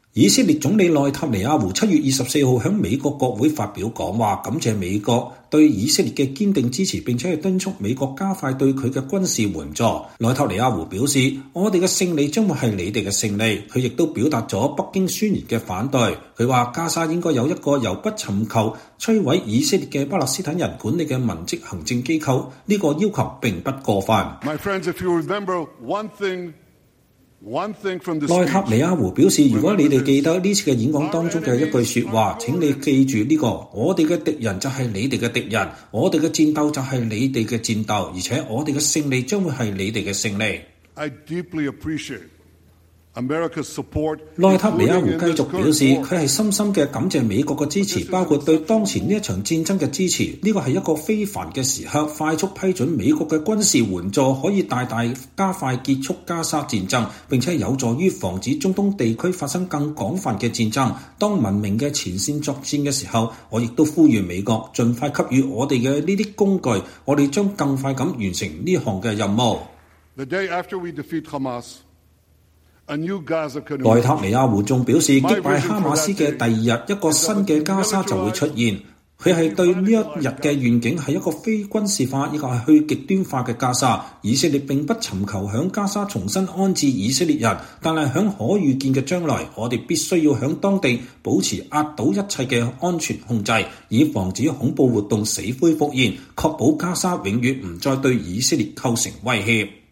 以色列總理內塔尼亞胡(Benjamin Netanyahu) 7月24日在美國國會發表講話。